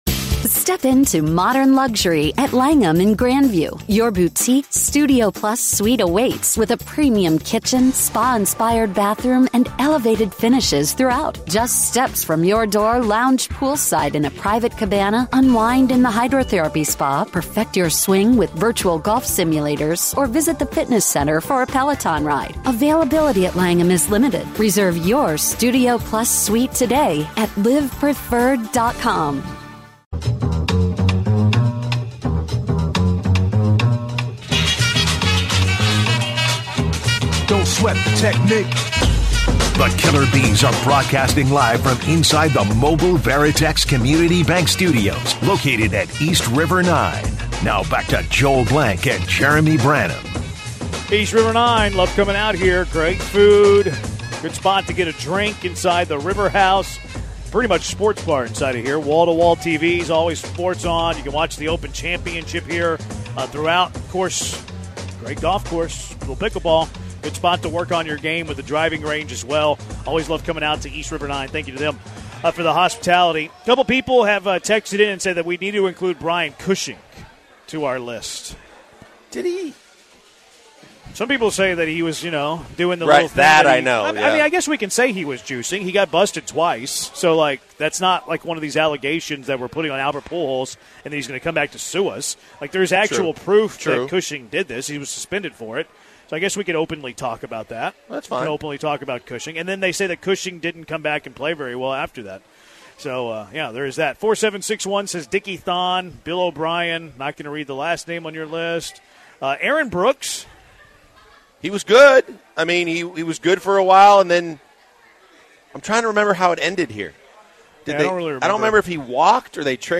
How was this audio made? LIVE from East River 9!